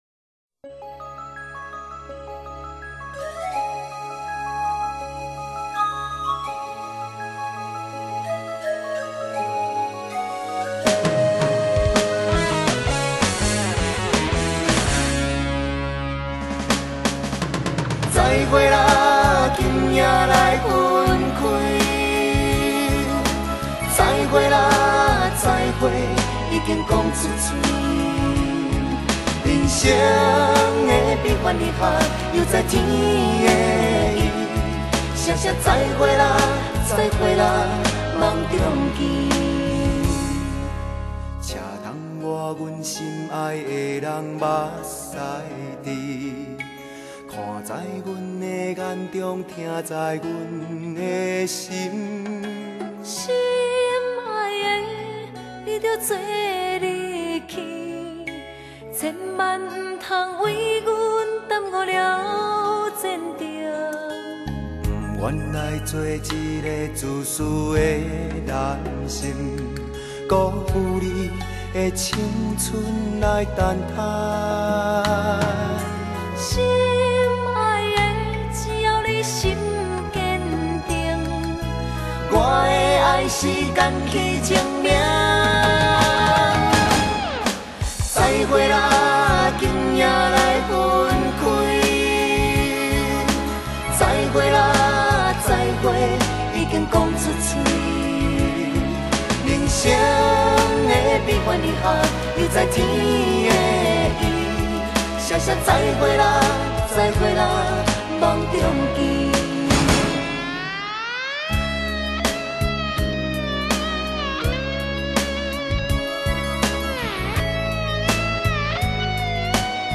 情歌對唱